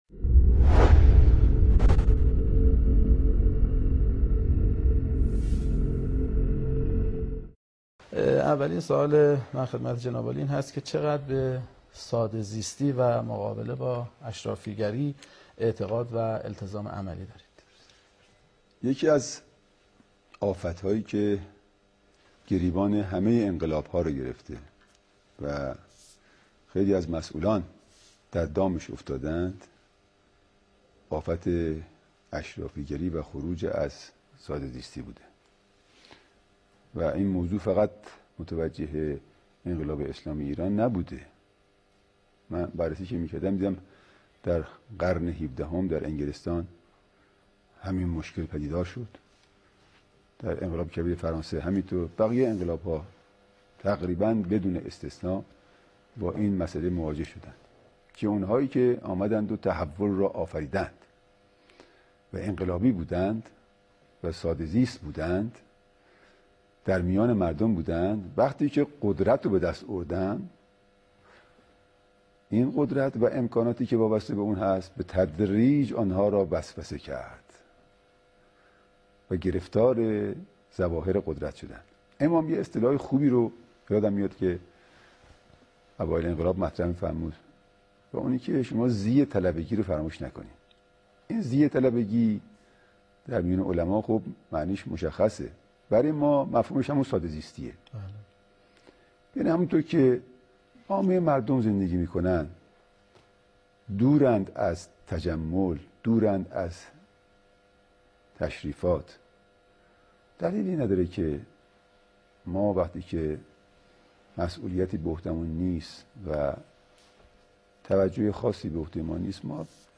به گزارش خبرنگار سیاسی خبرگزاری تسنیم، سیدمصطفی میرسلیم کاندیدای دوازدهمین دوره انتخابات ریاست‌جمهوری عصر امروز در شبکه چهارم سیما و در پاسخ به سؤالات کارشناسان، با اشاره به برنامه‌هایش درباره دانشگاه‌ها و پژوهشگاه‌ها، گفت: توجه به دانشگاه‌ها و پژوهشگاه‌ها دو دلیل خاص و عام دارد، دلیل عام آن این است که مسلمان هستیم و در اسلام اهمیت زیادی به بحث علم داده شده است.